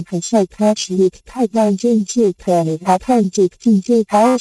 现在melgan训练到175epoch，合成不再是杂音了，但效果还是很差。